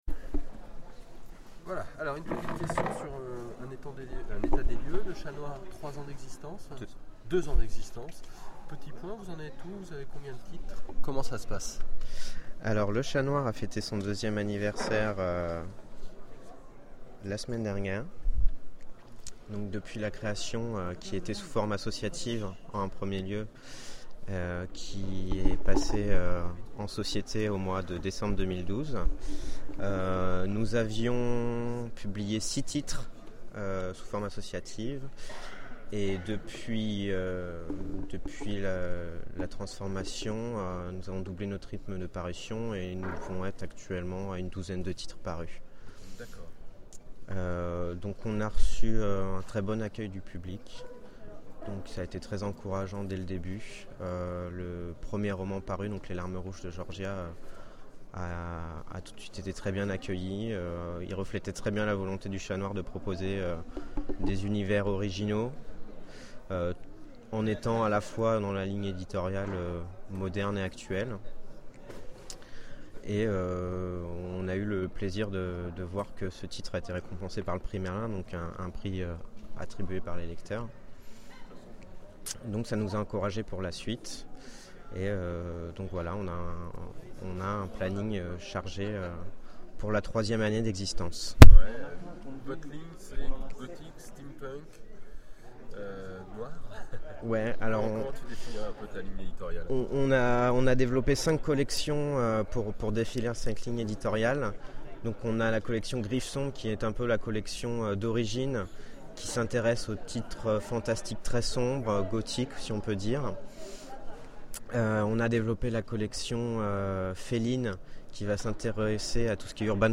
Interview Le Chat noir